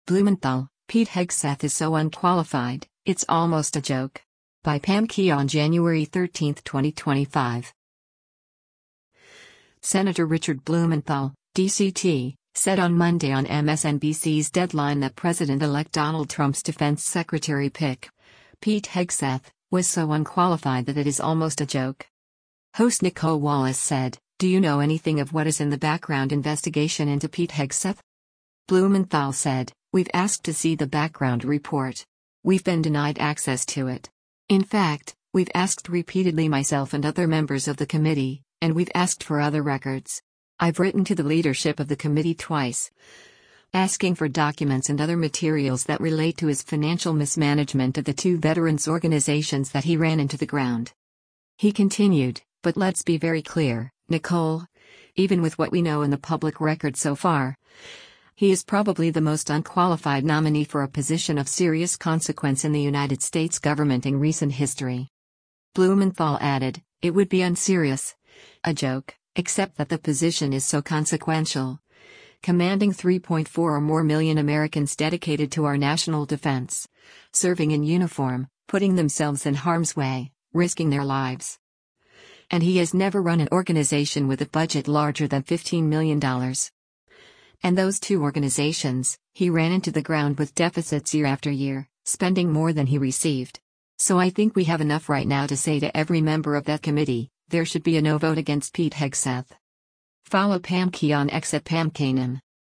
Senator Richard Blumenthal (D-CT) said on Monday on MSNBC’s “Deadline” that President-elect Donald Trump’s defense secretary pick, Pete Hegseth, was so “unqualified” that it is almost a “joke.”
Host Nicolle Wallace said, “Do you know anything of what is in the background investigation into Pete Hegseth?”